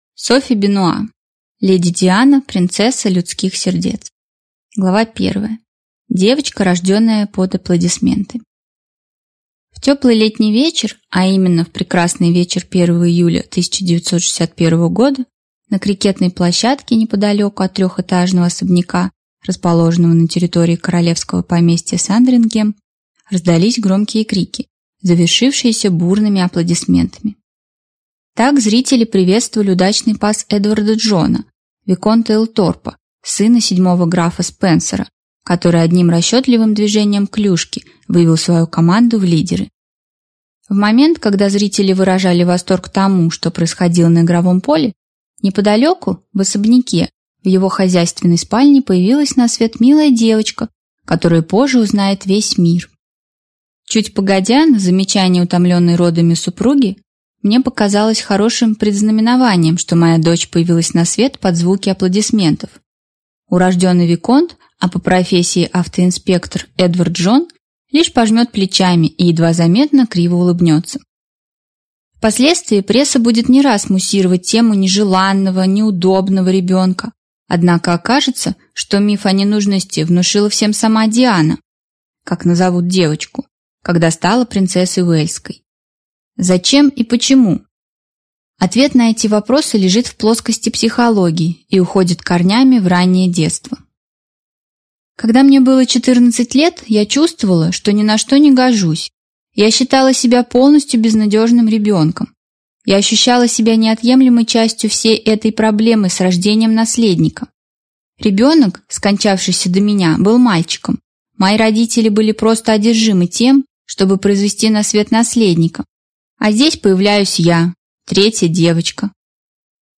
Аудиокнига Леди Диана. Принцесса людских сердец | Библиотека аудиокниг